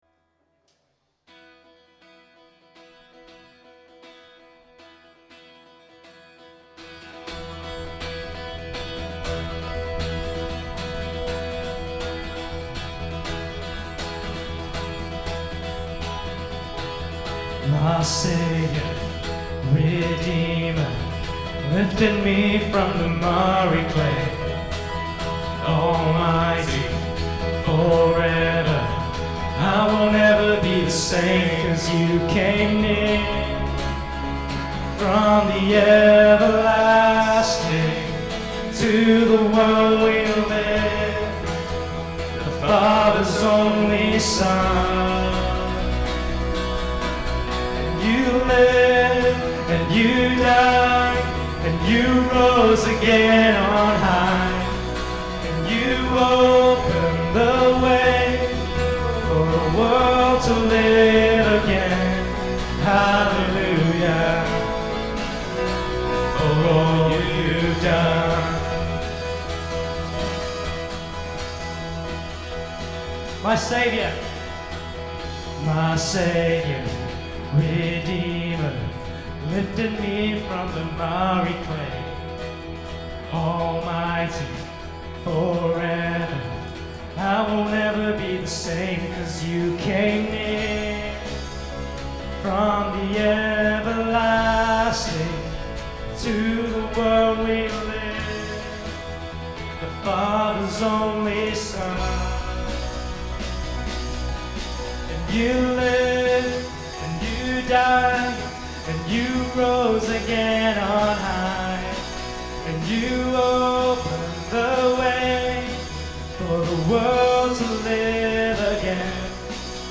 Vocals
Electric Guitar
Bass
Drums
Keys